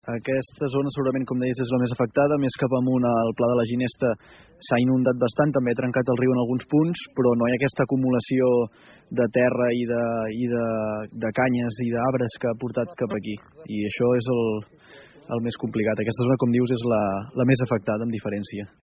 RP ha pogut parlar també aquest matí amb el regidor de Medi Ambient de l’Ajuntament de Palafolls, Aleix Freixa, que ha explicat que es destinaran tots els recursos disponibles a nivell municipal en aquest sector, i que a la vegada es treballarà per aconseguir ajuda a més alts nivells.